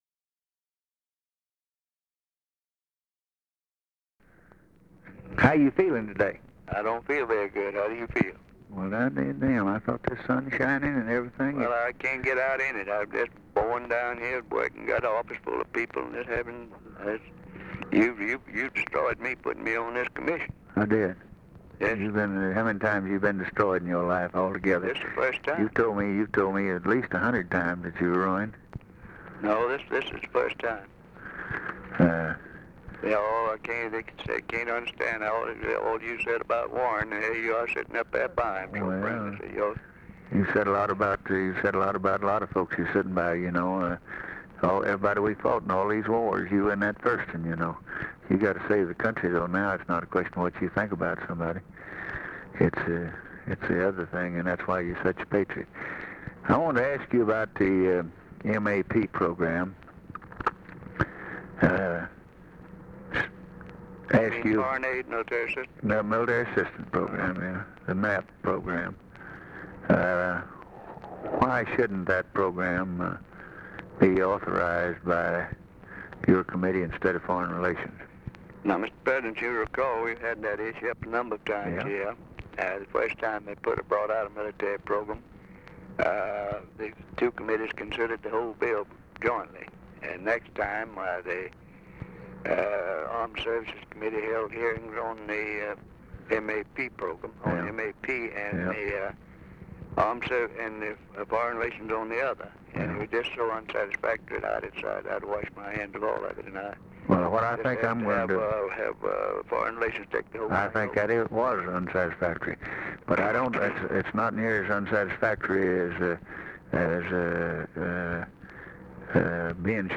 Conversation with RICHARD RUSSELL, December 7, 1963
Secret White House Tapes